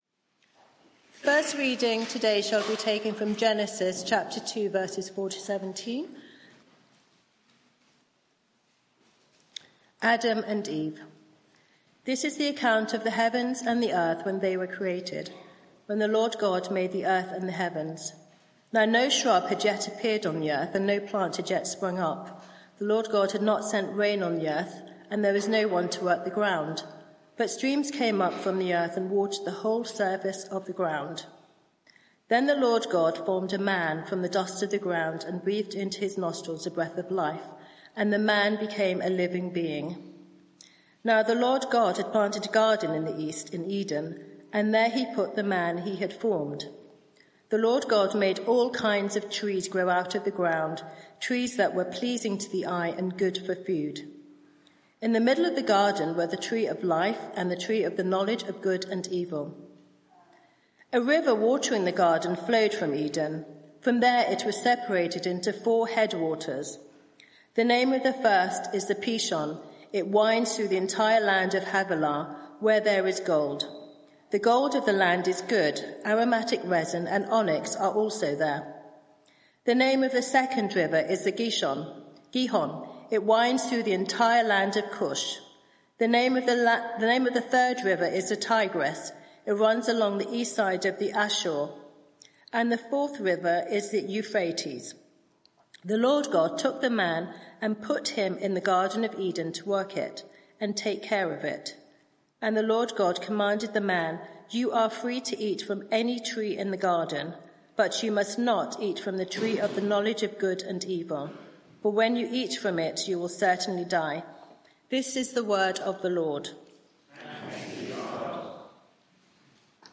Media for 6:30pm Service on Sun 20th Oct 2024 18:30 Speaker
Sermon (audio) Search the media library There are recordings here going back several years.